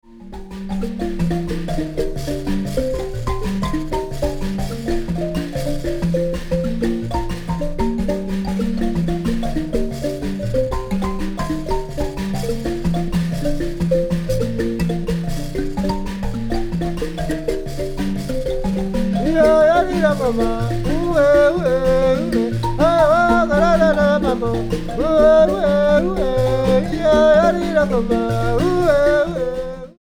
B flat nyamaropa tuning.